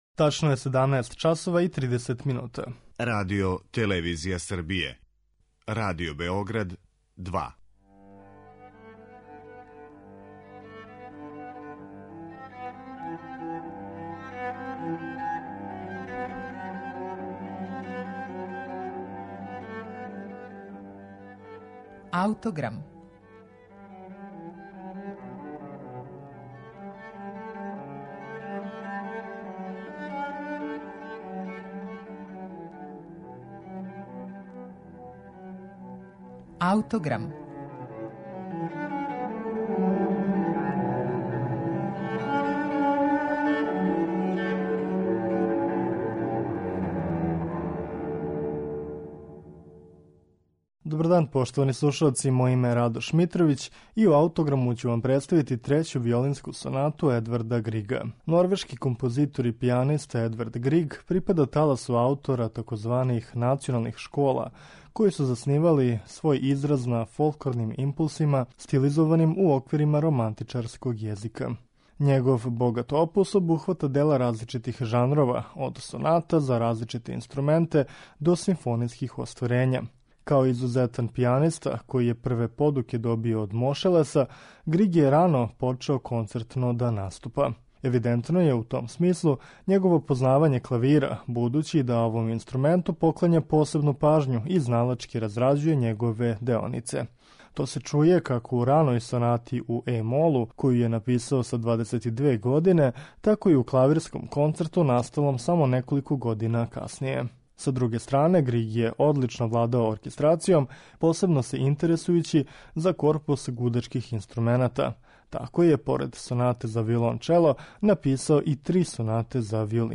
ГРИГ - ВИОЛИНСКА СОНАТА
Слушаћете је у извођењу Аугустина Думаја и Марије Жоао Пиреш.